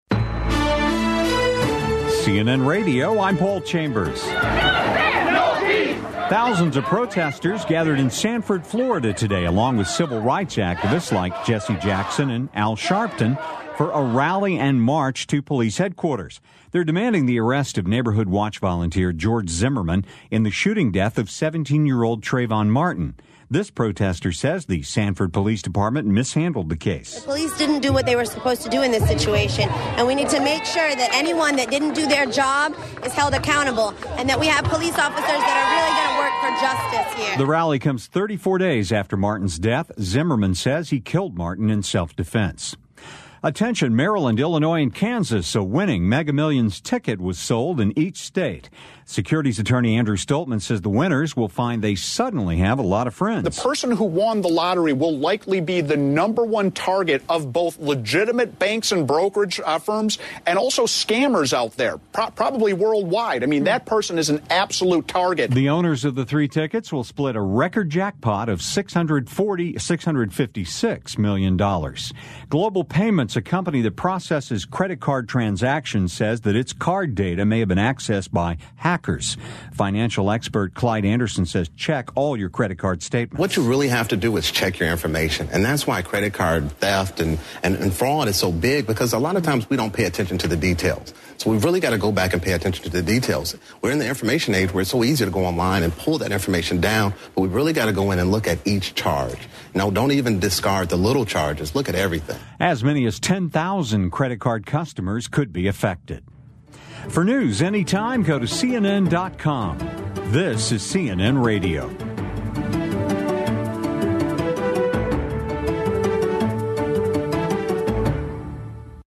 CNN News示威者称警察部门误导枪击案